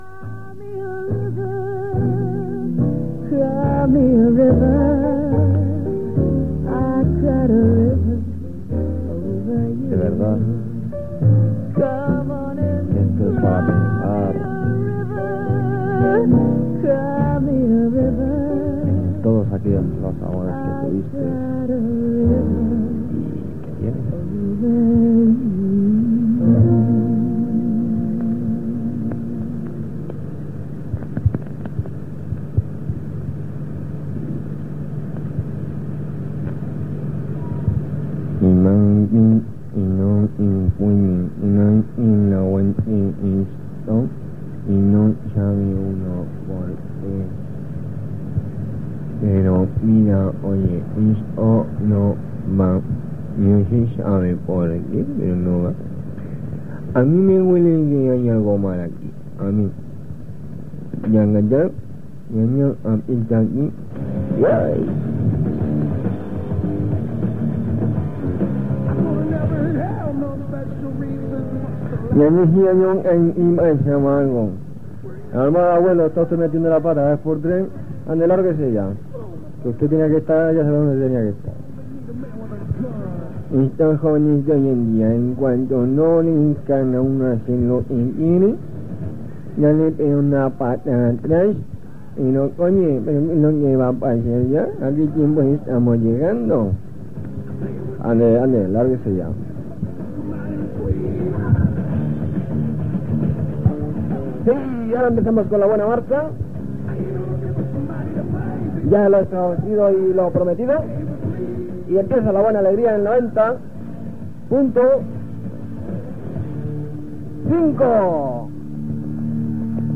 a5d95c8be1bcec0c6af822eb623a3fac3ccc23a3.mp3 Títol Radio 90.5 Emissora Radio 90.5 Titularitat Tercer sector Tercer sector Musical Descripció Tema musical, intervenció del "abuelo" i identificació, tema musical.